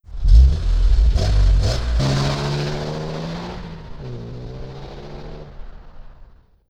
Título: Carro
Palavras-chave: Jogos educacionais; Foley
Resumo: Som de carro acelerando
Motor de carro (direita - esqurda).wav